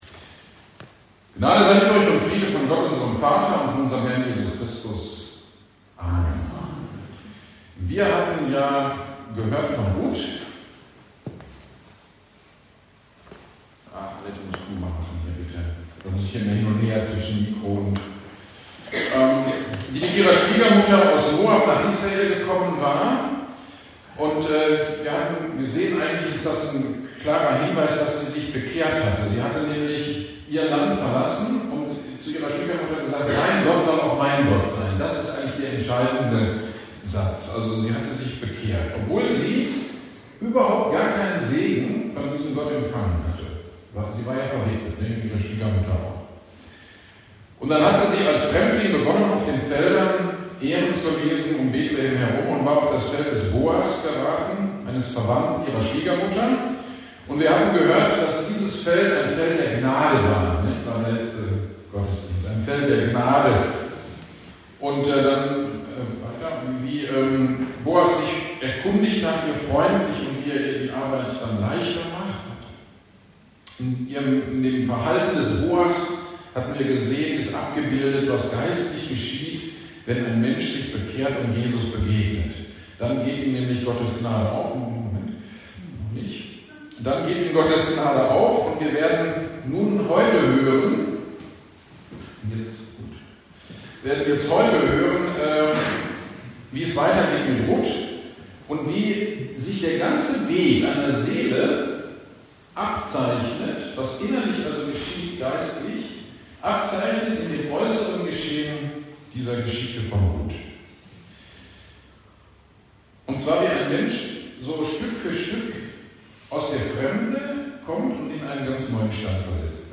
GD am 04.06.23 Predigt zu Rut 2.14-23